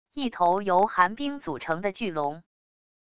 一头由寒冰组成的巨龙wav音频